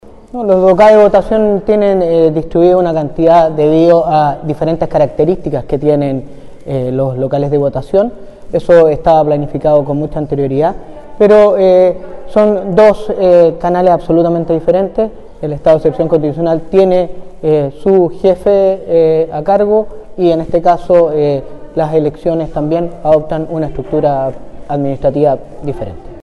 En Lebu, el Delegado de las fuerzas en la Provincia de Arauco, Capitán de Navío Cristian Magaña y el delegado provincial, Humberto Toro, realizaron un punto de prensa junto a funcionarios de Carabineros y PDI, con motivo de la coordinación de seguridad para las próximas elecciones del 4 de septiembre.